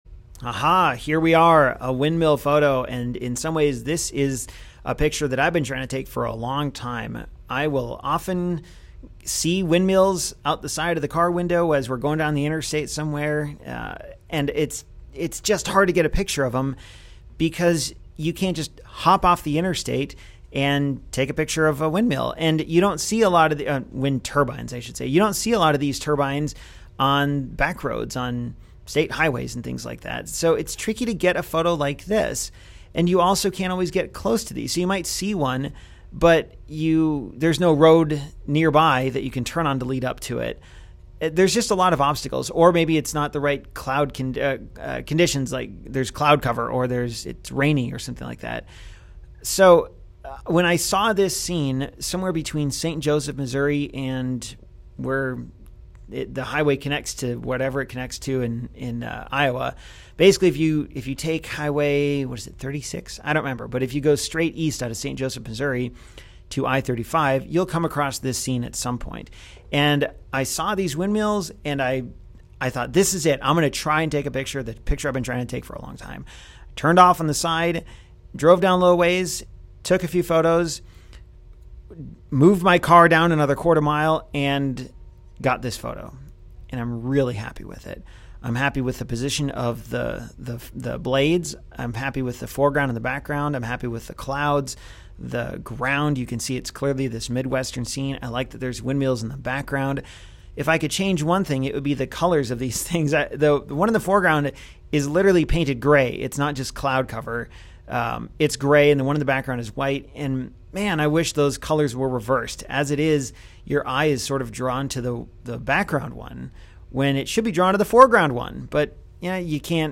Turbine
Turbine.m4a